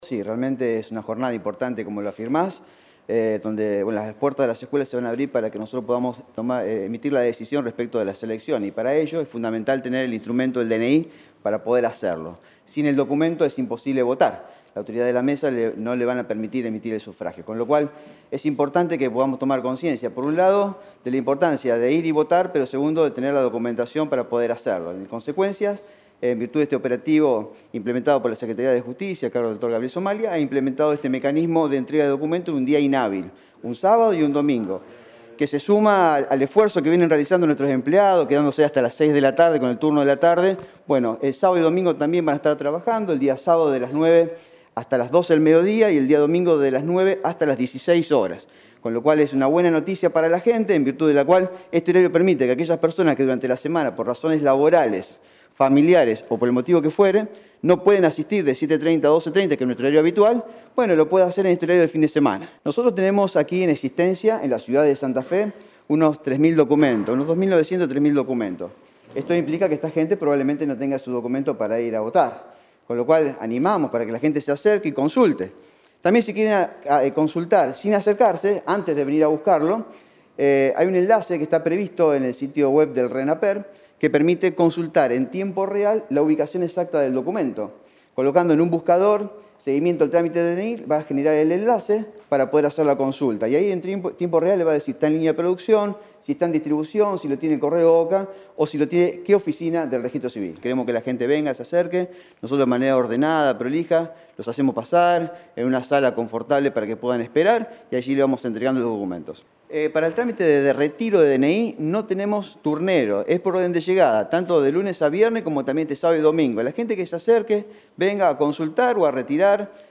Nota del director de Registro Civil de la provincia de Santa Fe.